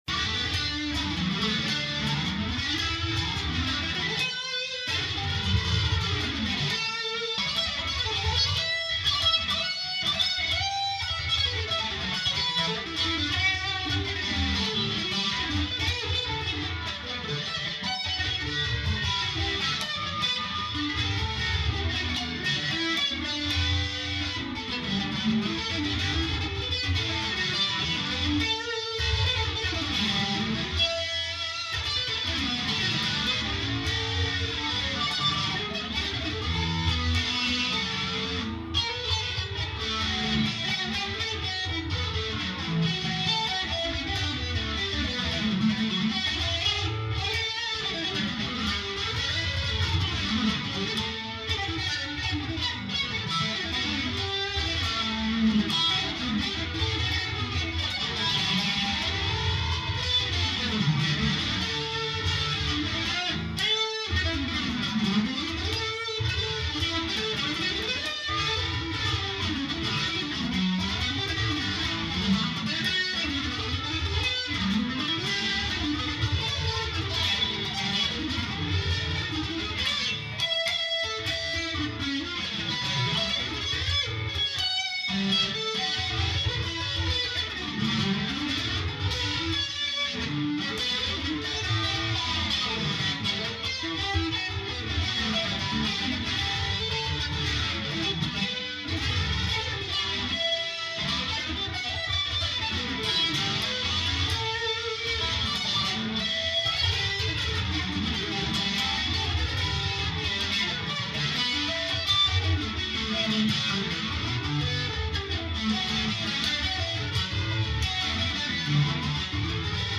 Warm-up sessions recorded from a PA mic in my echoy shop - on a $200 Samick guitar Shortclip D major warmup Comping on old blues tune Comping on old blues tune1
D-major.mp3